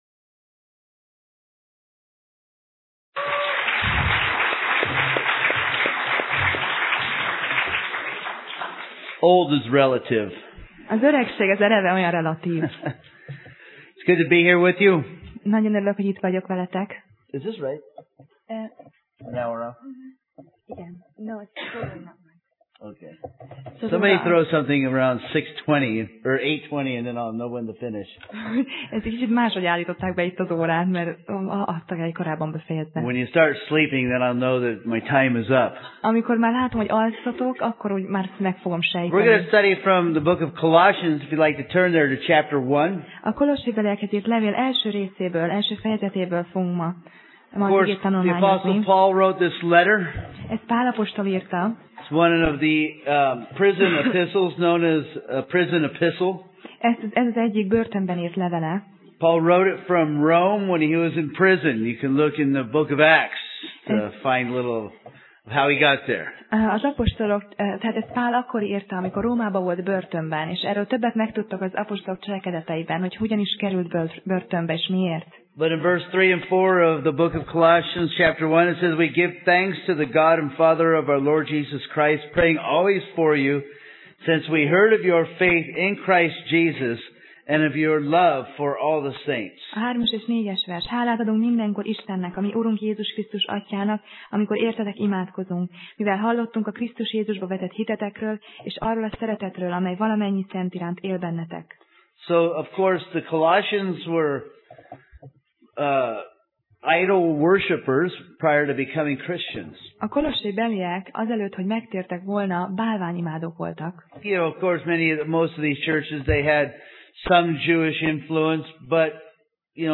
Tematikus tanítás
Alkalom: Szerda Este